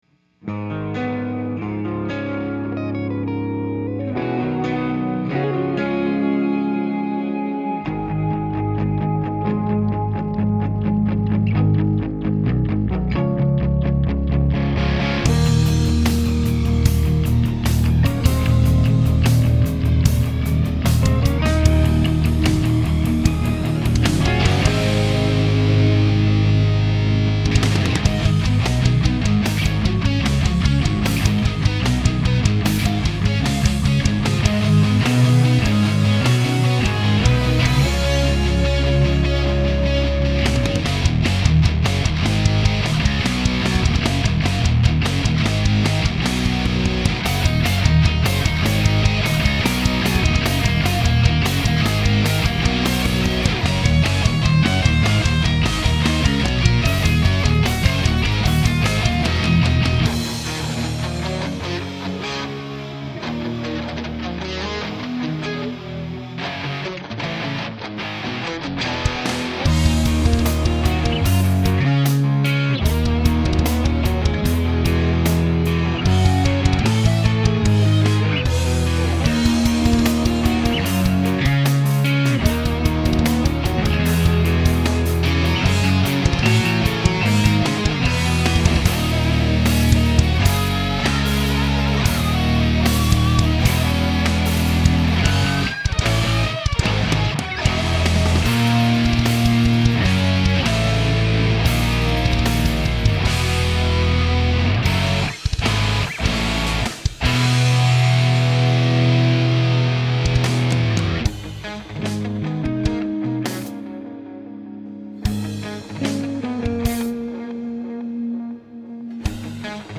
Advice on mixing instrumental prog rock/metal
My songs have tons of layered guitars and it's a big challenge to juggle and make them all fit within the mix.